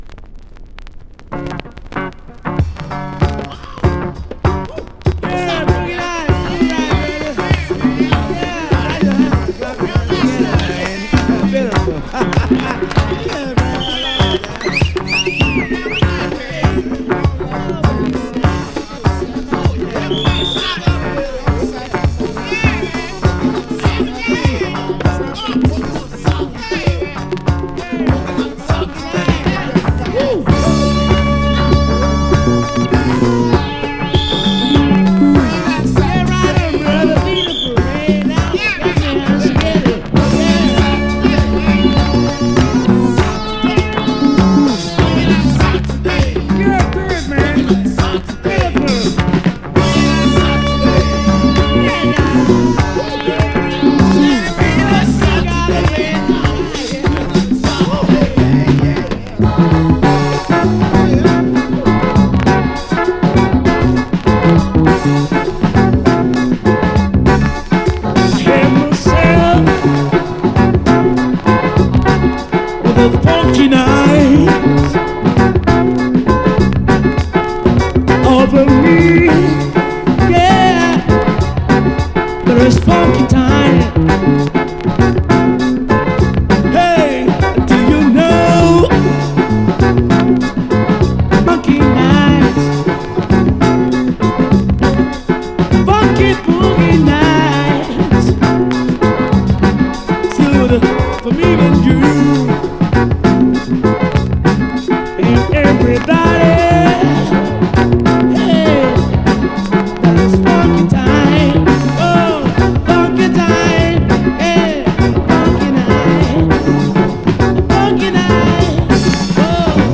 Media looks NM-, some crackles.